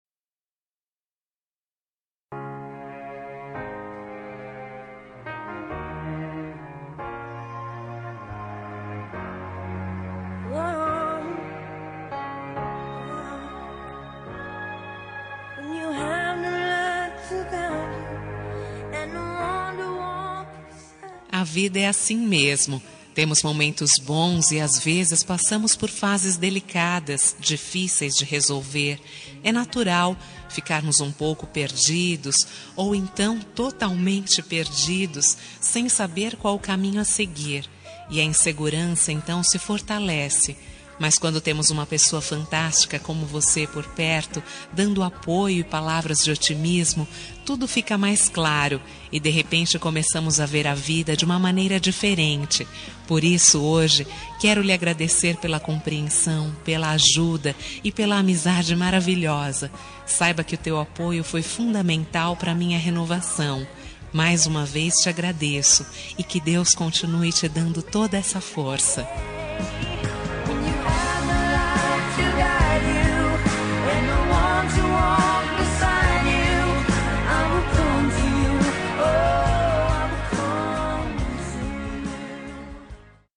Telemensagem de Agradecimento – Pelo Apoio – Voz Feminina – Cód: 09